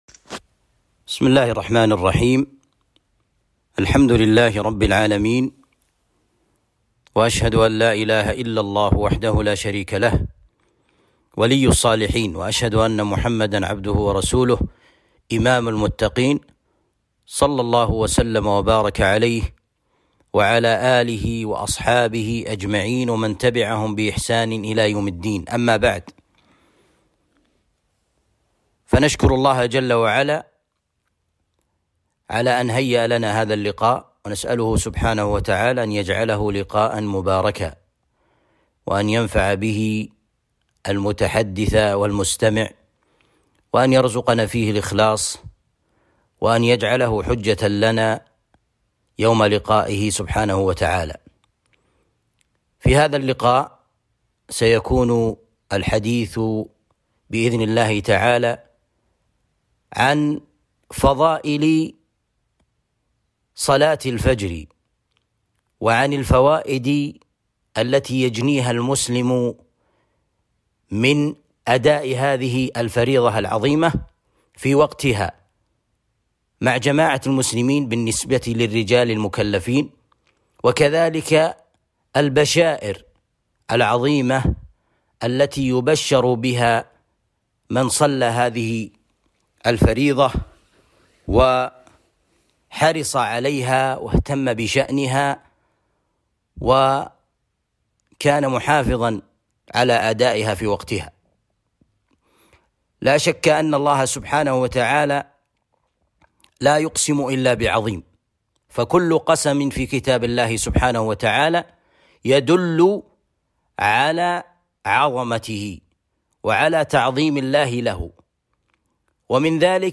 محاضرة بعنوان البشائر العشر للمحافظين على صلاة الفجر